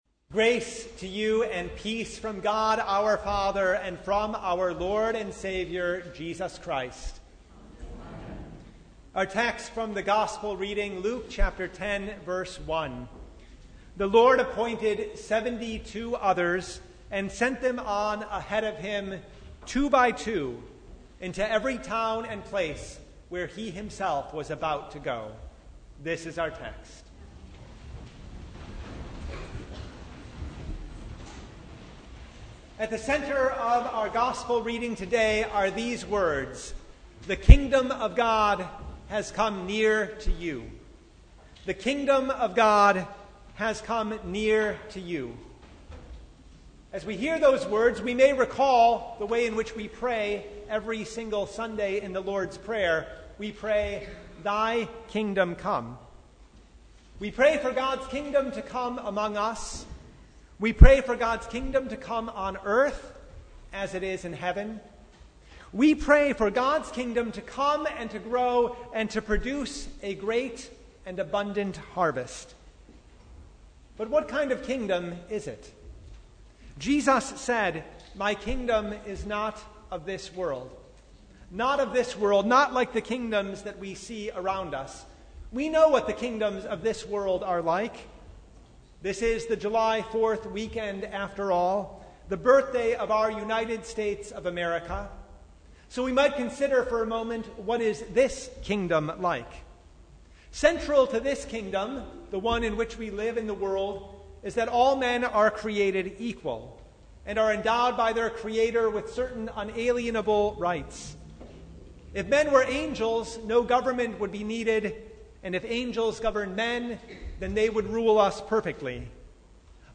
Sermon from The Second Sunday in Apostles’ Tide (2022)
Sermon Only